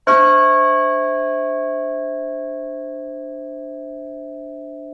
Church Bells
29" Vanduzen 1889 Click to hear this bell